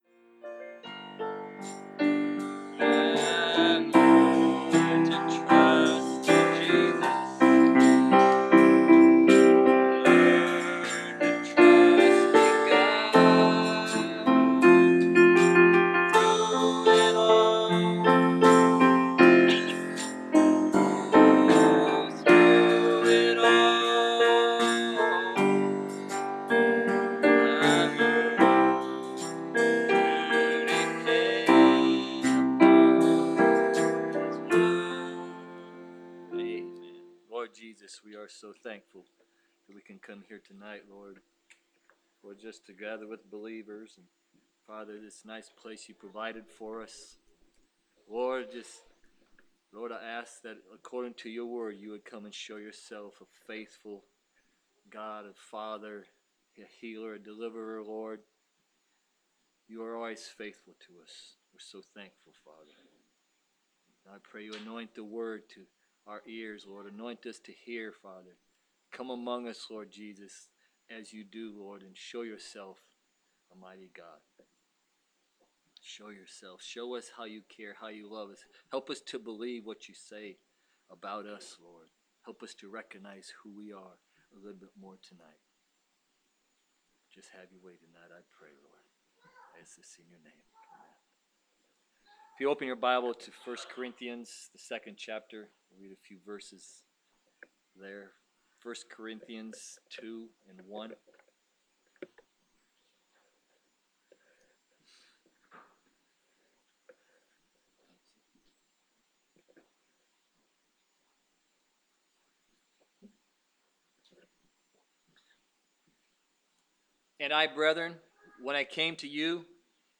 Significance of Jannes And Jambres Pt2 – Bible Believers Tabernacle